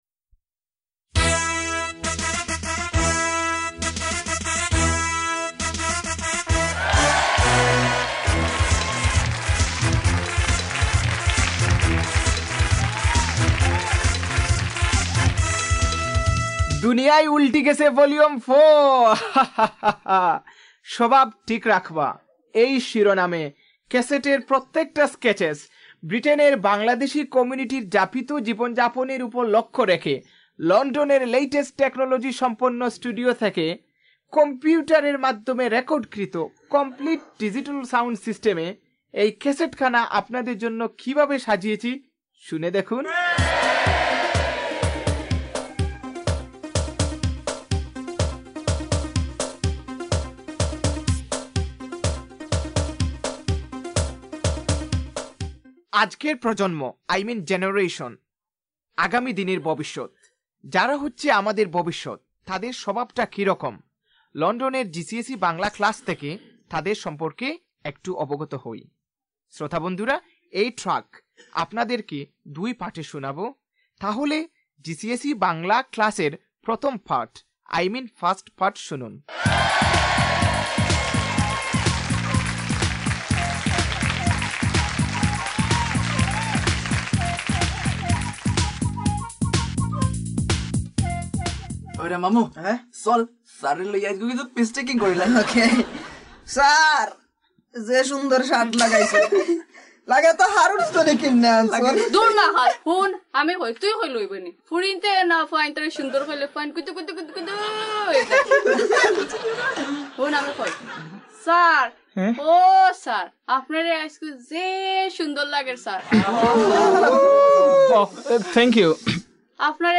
Shovab Part 1 – Comedy